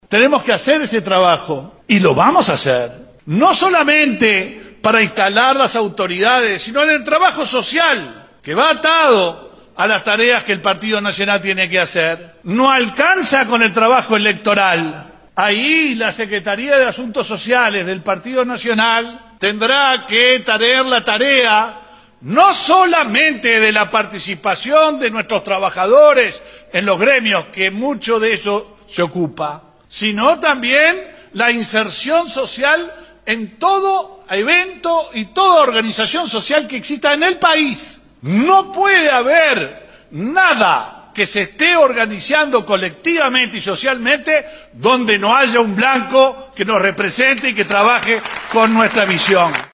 En su discurso, Heber dijo que el partido tiene que estar presente en todas las actividades del país.